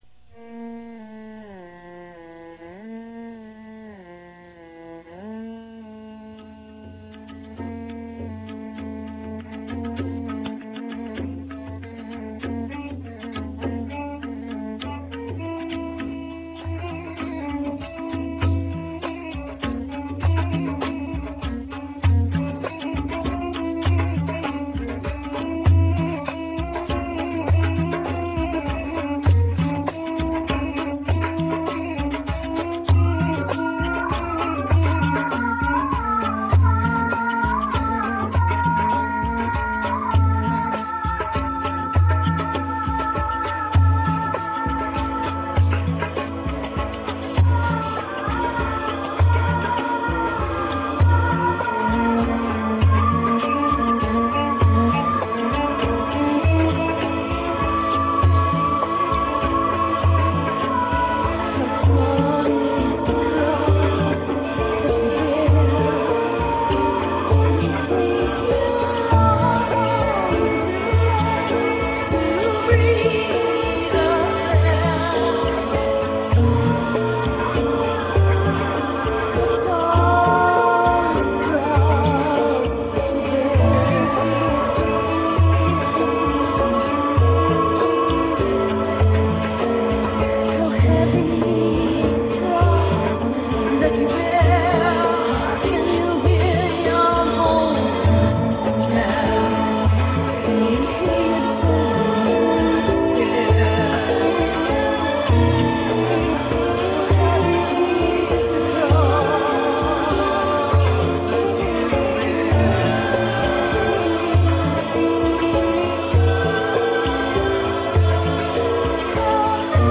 World
There are no guitars to be found.